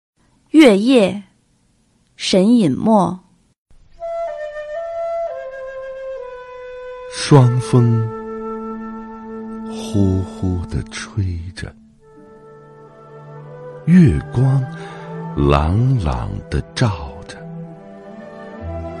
九年级语文下册 3《月夜》男声配乐朗读（音频素材）